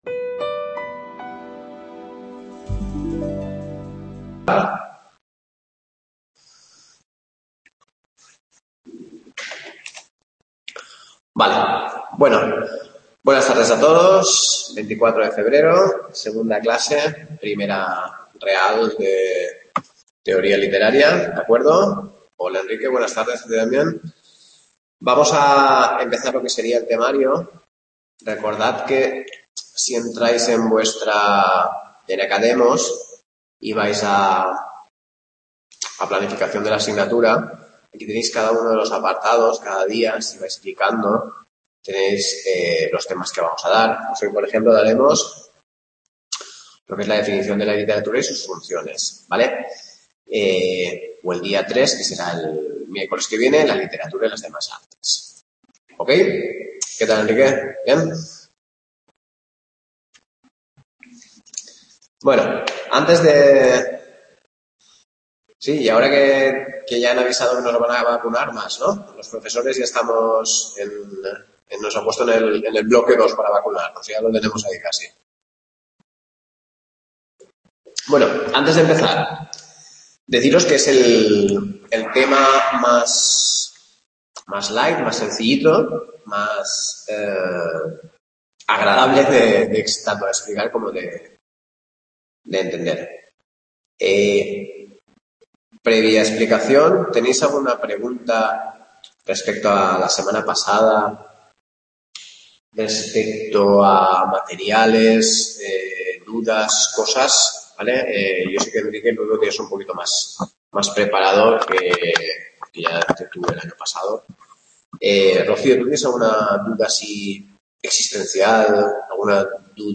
Clase 2 Literatura y sus funciones